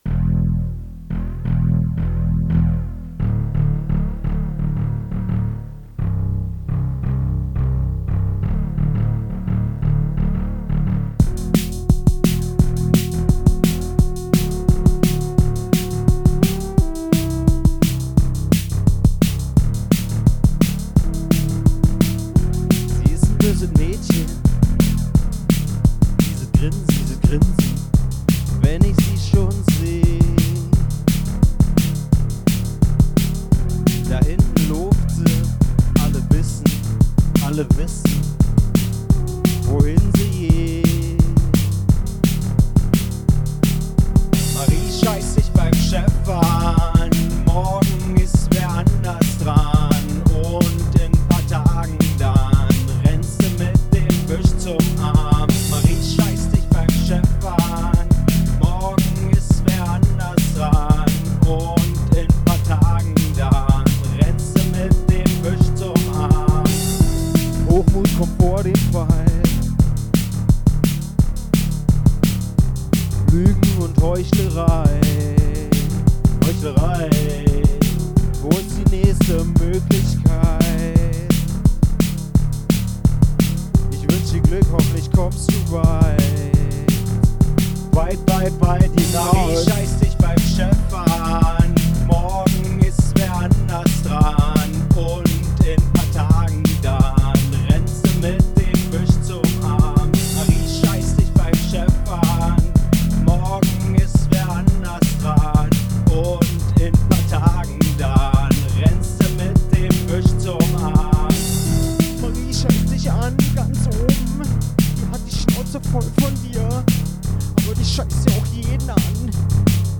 Seit ungefähr einem Monat, hab ich jetzt auch angefangen, meine Stimme in Produktionen zu nutzen und will euch jetzt damit bereichern . Der Song heißt „Marie scheißt dich an.“
Punk